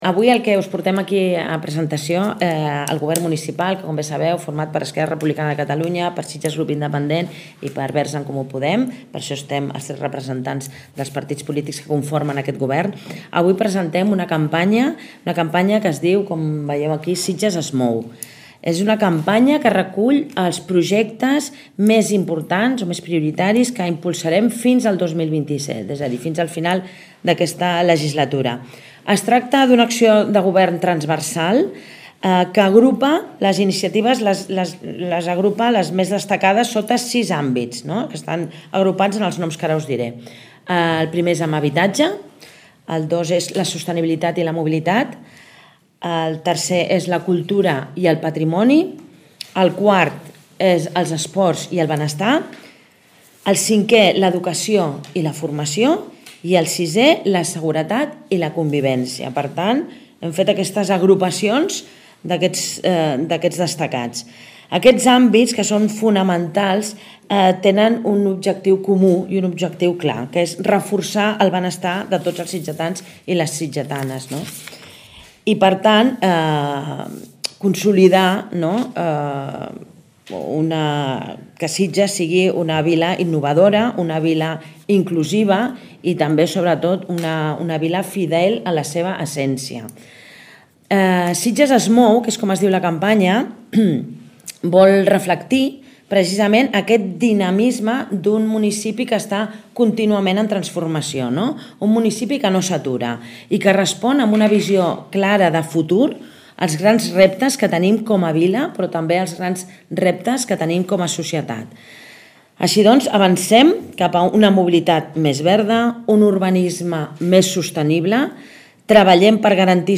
L’alcaldessa de Sitges, Aurora Carbonell, acompanyada els caps de llista de les dues formacions que donen suport al govern, David Martínez i Carme Gasulla, ha explicat que la lista exemplifica els grans reptes, amb les necessitats de present i de futur.